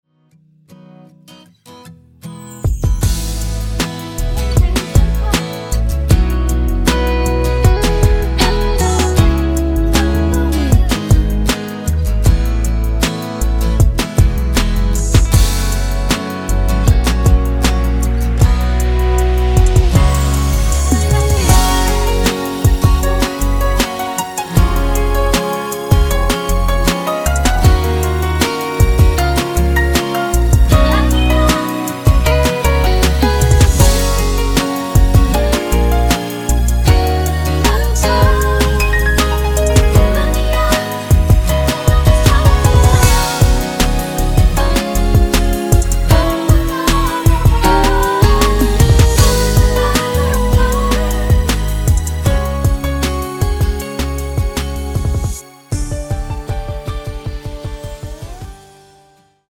음정 원키 3:07
장르 가요 구분 Voice MR